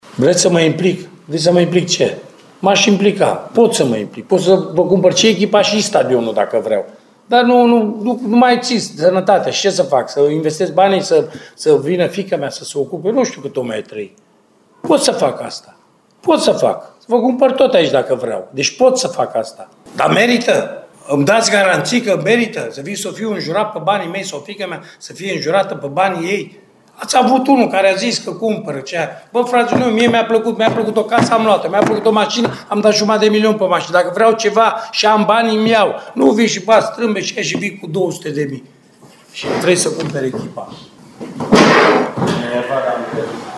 Antrenorul UTA-ei, Mircea Rednic, a avut un acces de furie, ieri, la finalul partidei cu Universitatea Craiova.
Rednic a fost deranjat în principal de problemele financiare de la club și de relația cu suporterii. Tehnicianul a spus că ar putea cumpăra și echipa și stadionul din Arad, ultima parte a declarației făcând-o după ce a trântit scaunul și în drumul către ieșirea din sala de conferințe: